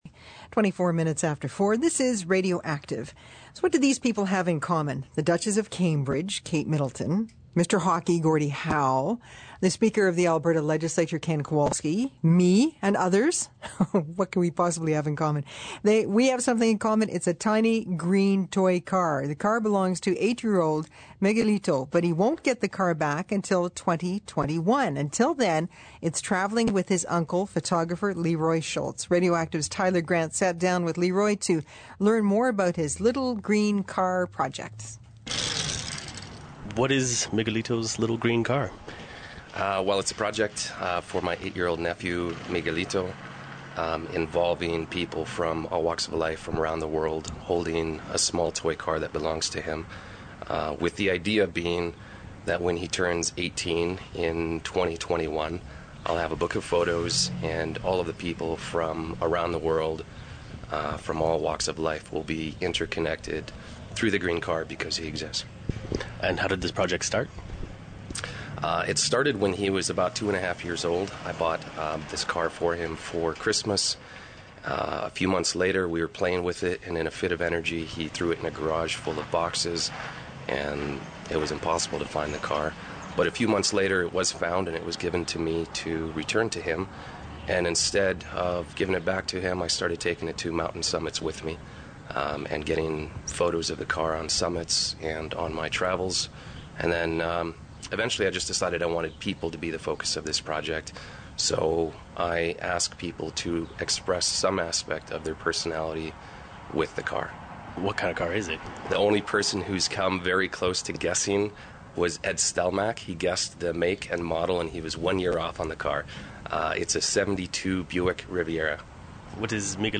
The ambient noise during the interview is from the traffic that skirted us while we shot and recorded.  Click below to listen to the interview broadcast on CBC Radio 1 on February 26, 2012.
cbcradio1radioactivemlgcinterview.mp3